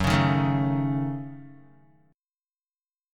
F#m6 chord